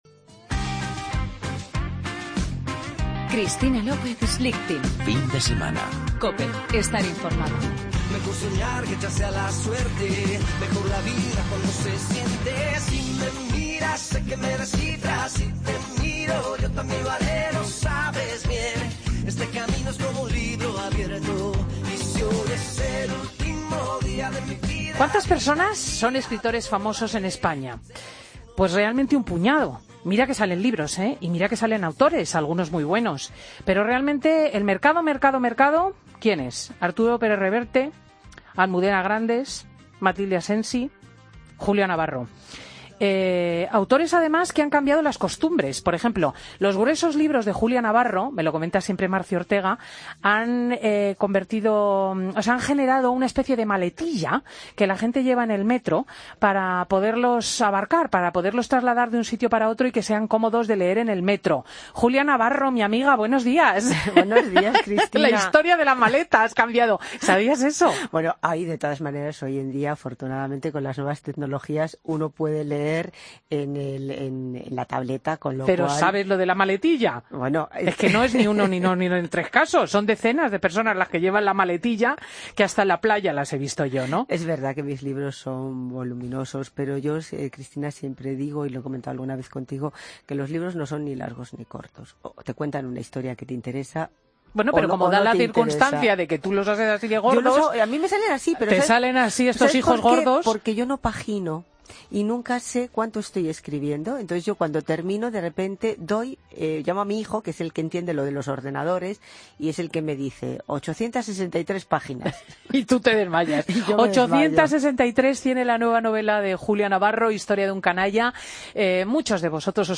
Escucha la entrevista a Julia Navarro, autora del libro 'Historia de un canalla', en Fin de Semana COPE
Madrid - Publicado el 12 mar 2016, 11:24 - Actualizado 17 mar 2023, 17:51